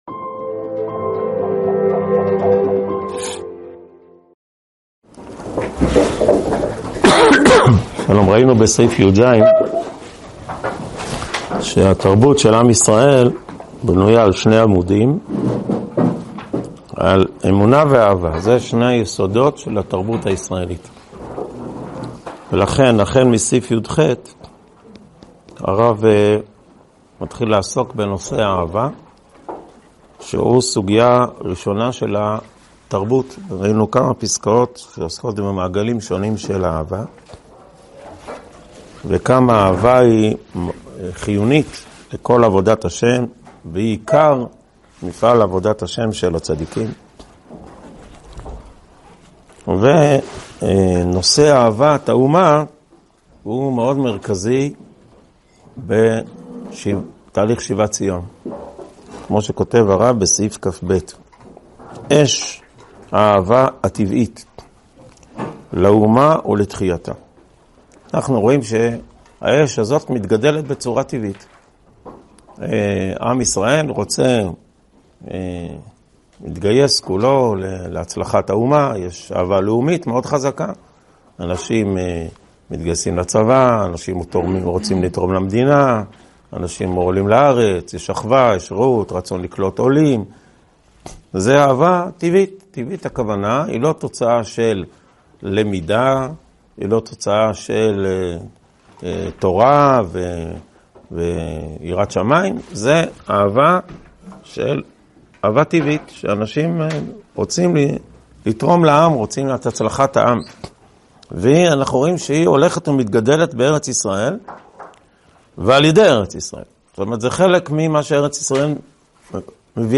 שיעור 29 מתוך 59 בסדרת אורות התחיה
הועבר בישיבת אלון מורה בשנת תשפ"ד.